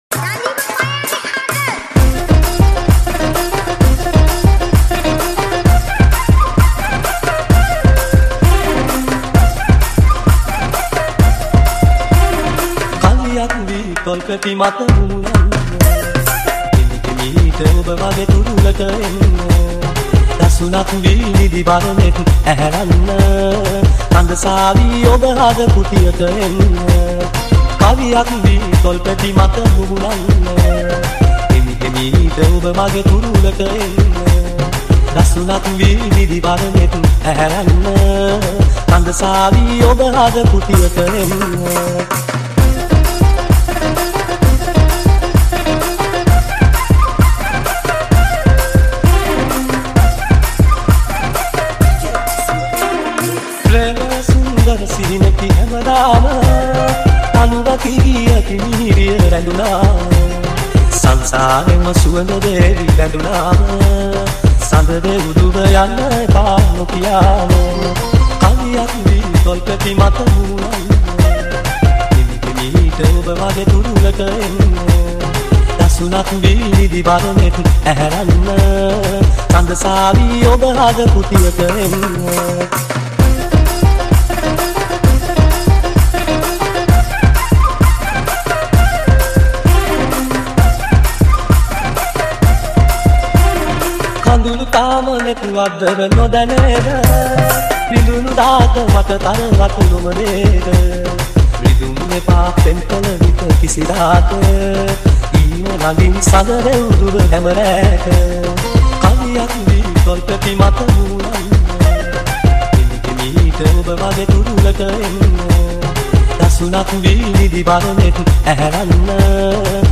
Sinhala DJ Remix